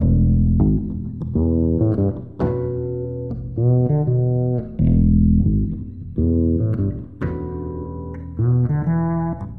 标签： bass five_fourths fretless groove
声道单声道